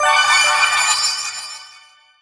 magic_general_item_collect_04.wav